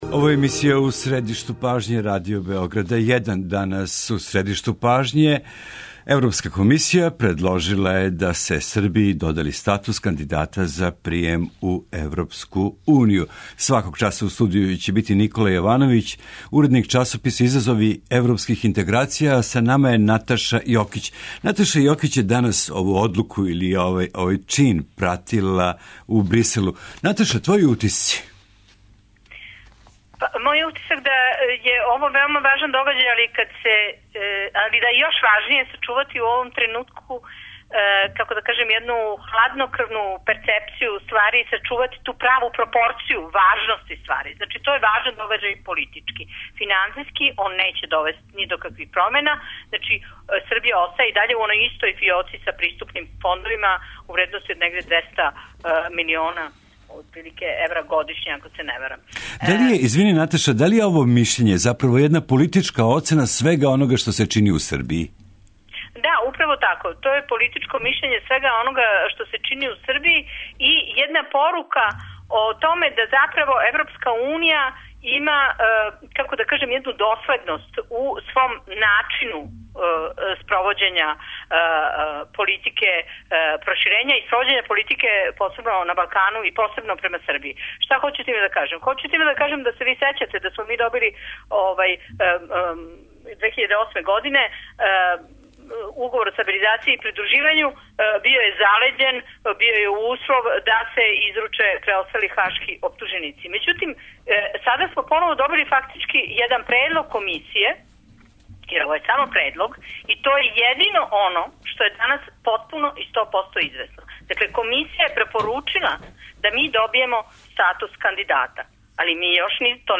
Чућемо, такође, и дописнике из Брисела и пратити прва реаговања широм Србије.